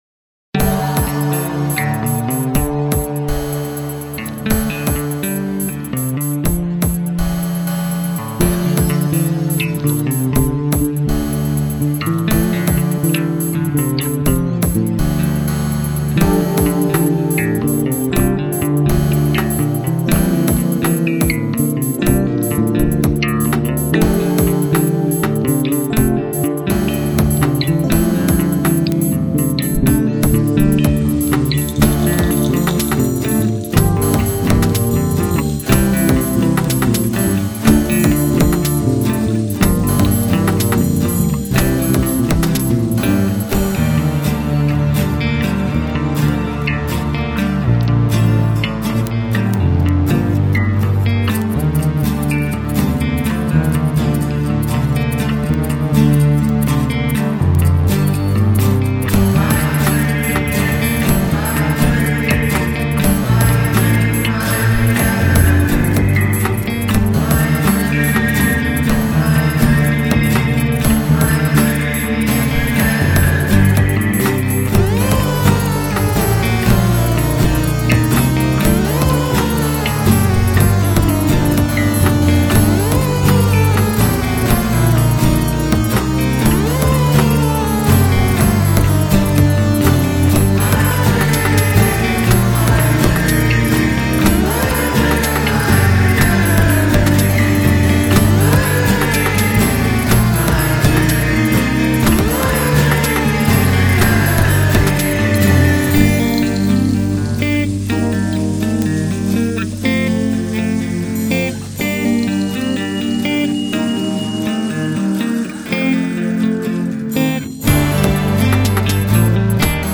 electronic pop-rock
Recorded in Felina (Reggio Emilia, Italy) in august 2003